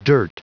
Prononciation du mot dirt en anglais (fichier audio)
Prononciation du mot : dirt